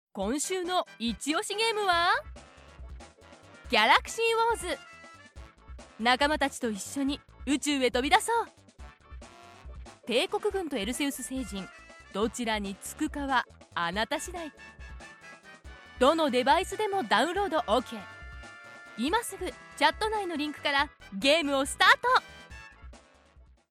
Female
TEENS, 20s, 30s
Sometimes, she is pure and friendly like a girl next door, at other times trustworthy, intellectual and authentic, on other occasions serious, and furthermore, can perform for cartoon characters.
Microphone: zoom H4n pro